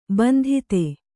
♪ bandhite